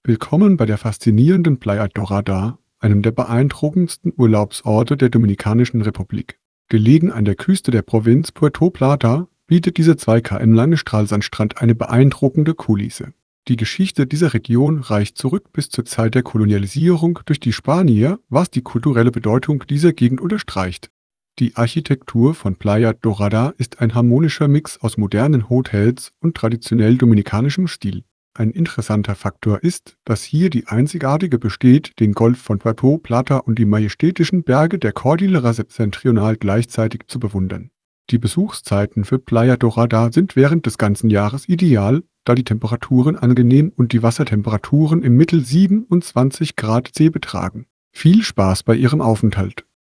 tts